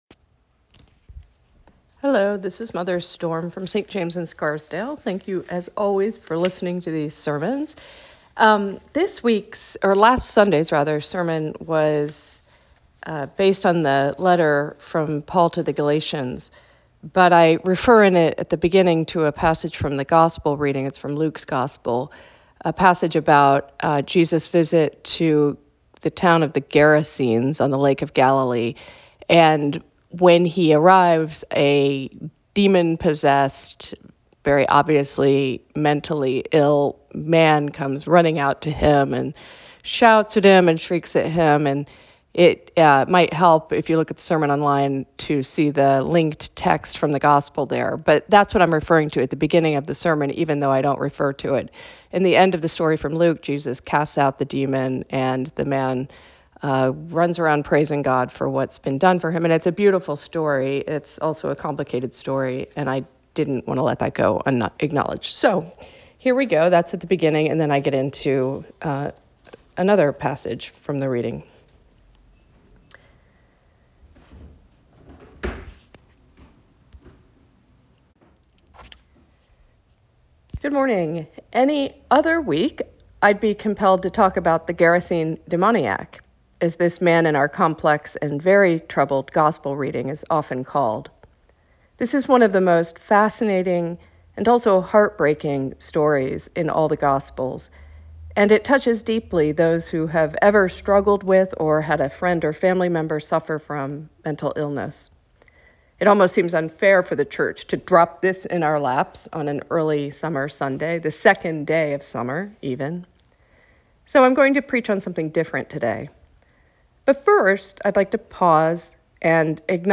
Sermon Podcast | Church of St. James the Less